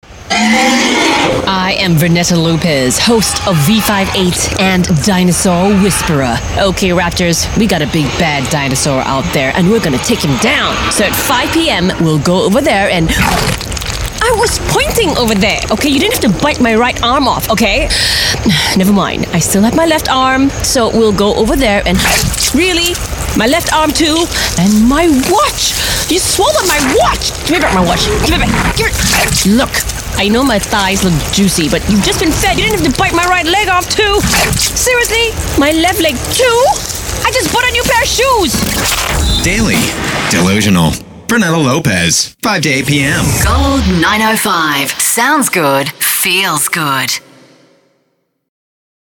Voice Samples: Radio Promo
EN Asian
female